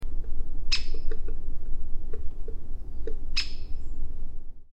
Der Buntspecht
Buntspecht_audio.mp3